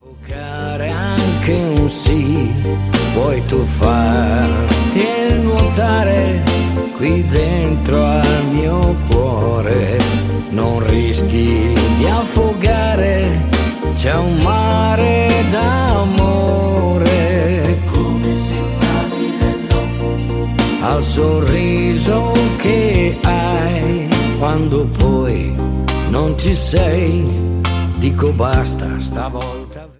TERZINATO  (03,42)